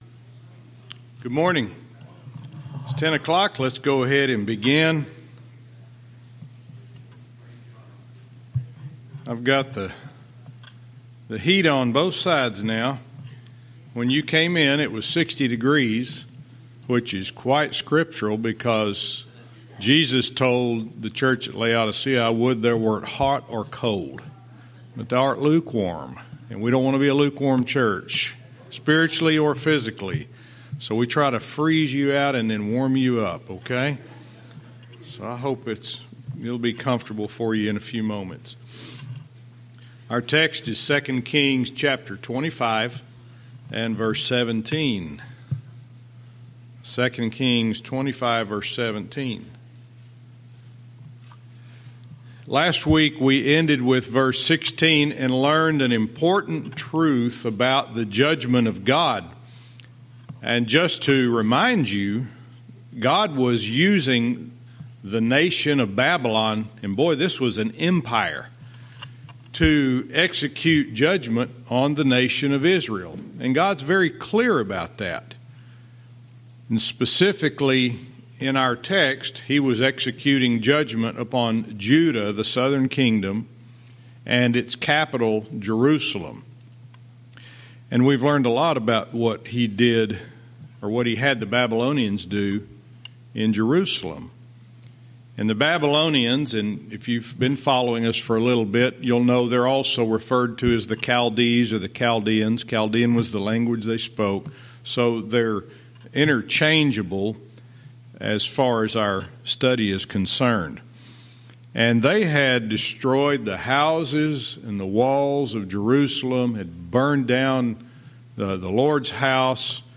Verse by verse teaching - 2 Kings 25:17-21